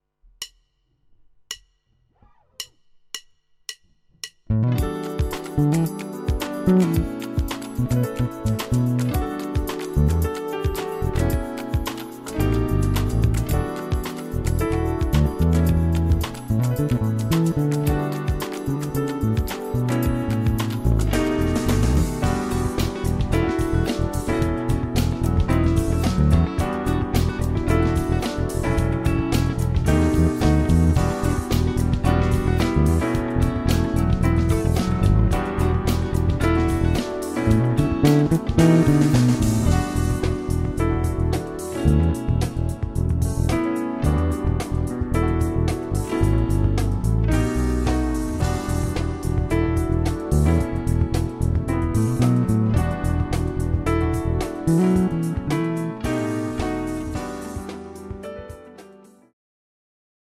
Ukázka 2 - krkový snímač, VTC na 50%, bass boost 10%